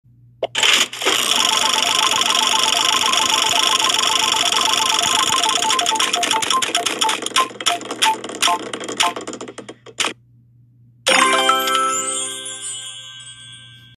spin.mp3